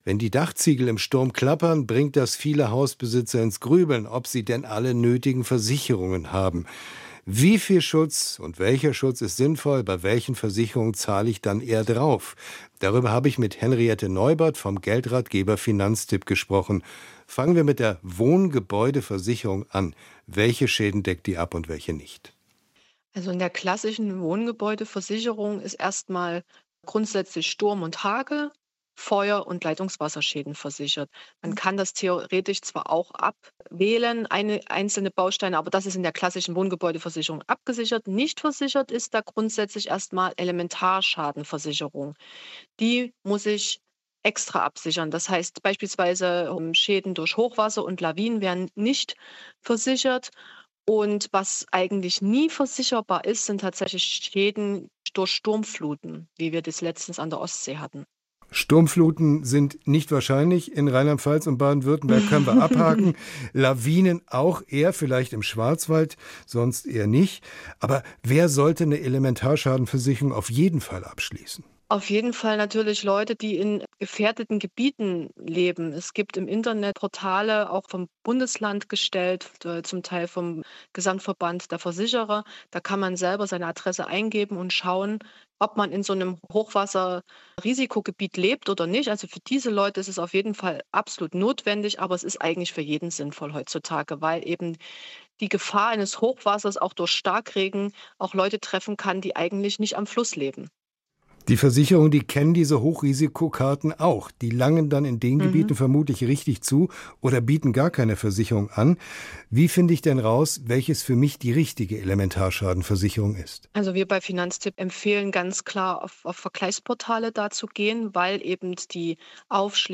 3. Radio